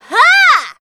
assassin_w_voc_attack03_d.ogg